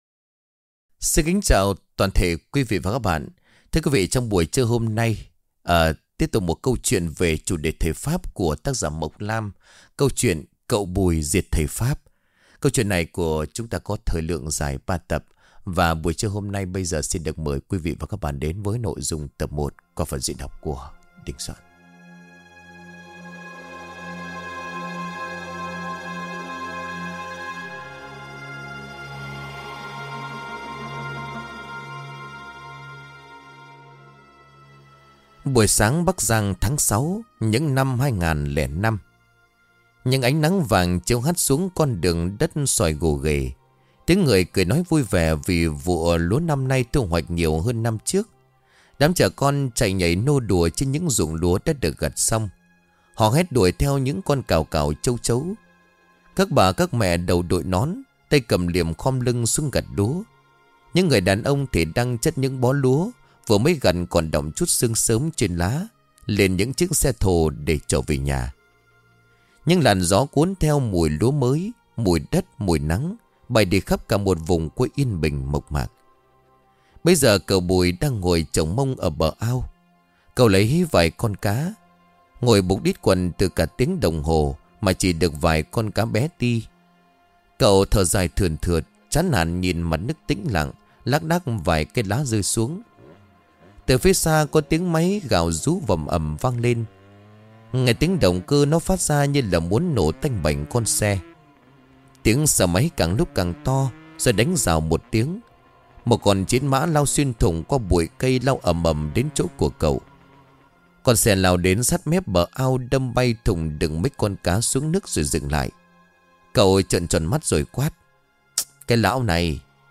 Truyện Ma | CẬU BÙI DIỆT THẦY PHÁP - Xuất Hồn Bắt Ấn Diệt Tà Tu